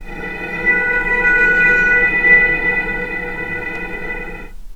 healing-soundscapes/Sound Banks/HSS_OP_Pack/Strings/cello/sul-ponticello/vc_sp-B4-pp.AIF at bf8b0d83acd083cad68aa8590bc4568aa0baec05
vc_sp-B4-pp.AIF